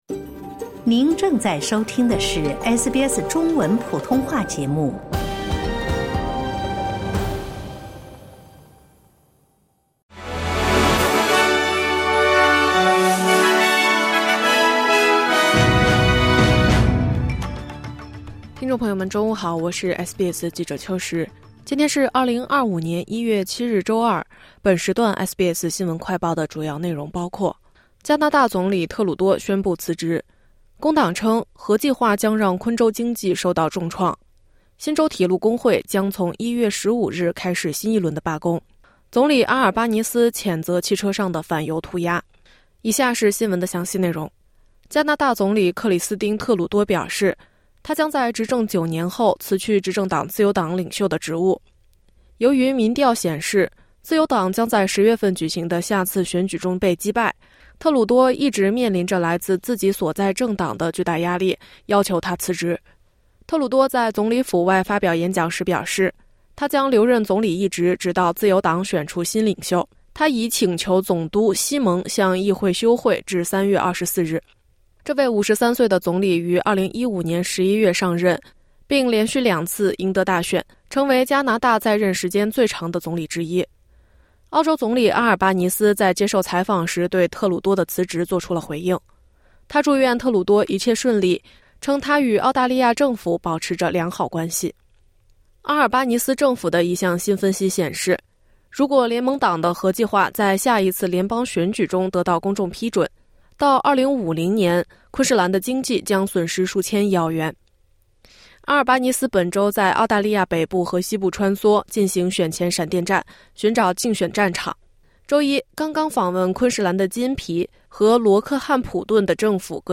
【SBS新闻快报】加拿大总理特鲁多宣布辞职